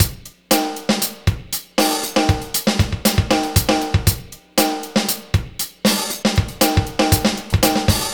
Gliss 2fer 1 Drumz.wav